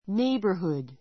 nei gh borhood néibə r hud